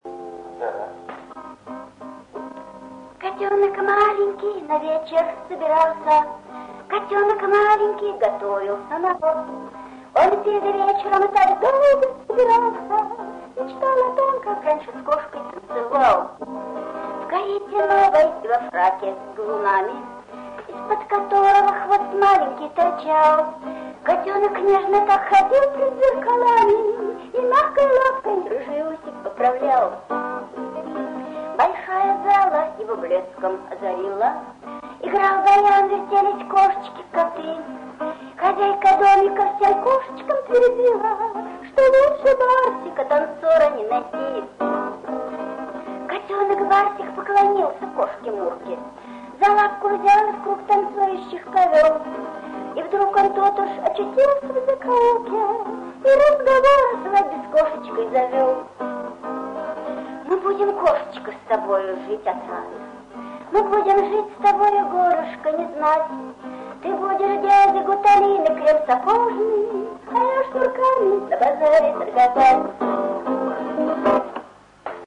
Даже вряд ли в "далёком курносом детстве": здесь явно женский голос!